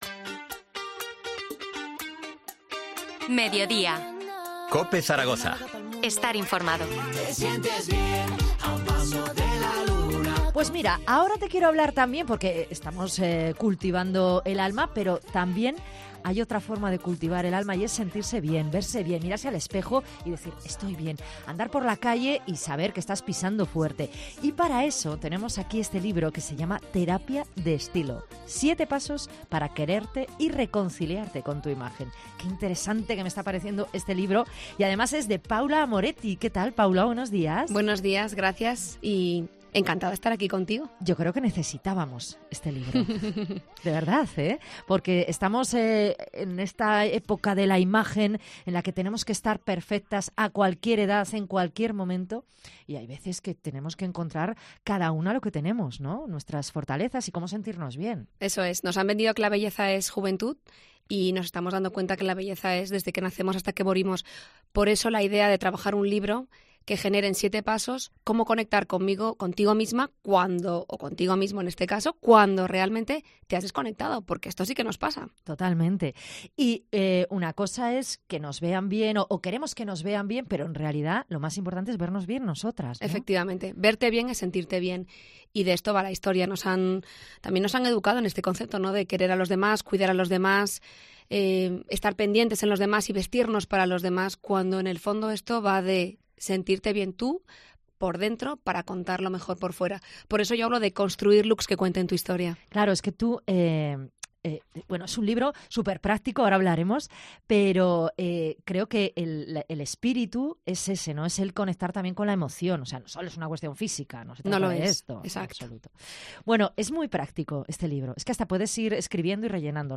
Entrevista a la estilista zaragozana